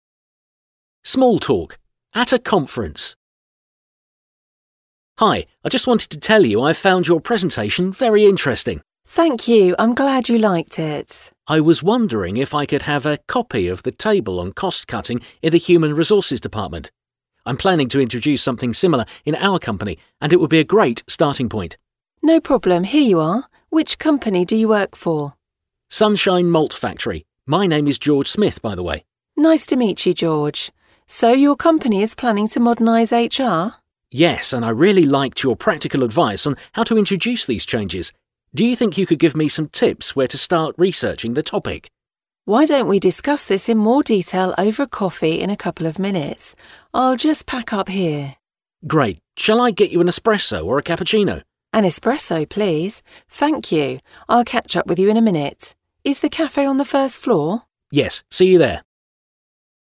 Ha angolul kell konferencián részt venned, akkor ez az angol párbeszéd jó jöhet.